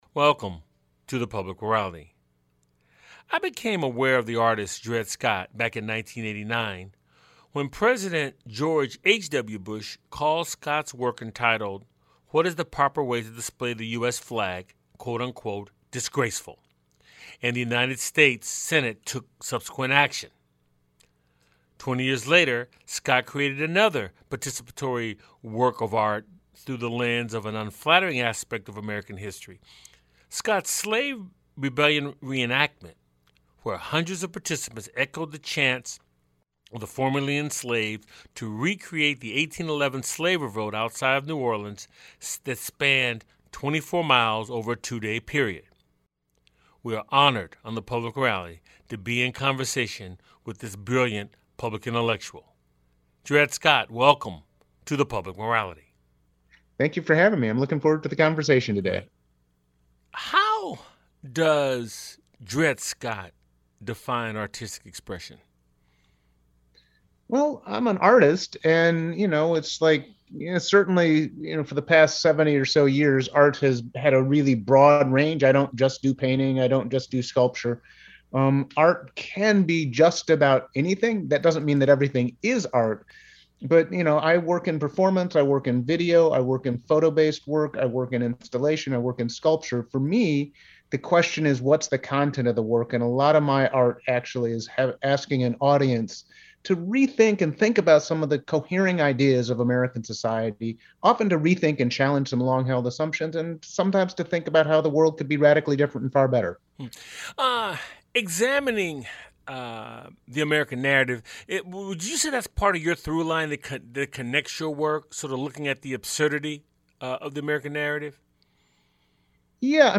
The guest on this show is Artist Dread Scott. It's a weekly conversation with guest scholars, artists, activists, scientists, philosophers, and newsmakers who focus on the Declaration of Independence, the Constitution and the Emancipation Proclamation as its backdrop for dialogue on issues important to our lives. The show airs on 90.5FM WSNC and through our Website streaming Tuesdays at 7:00p.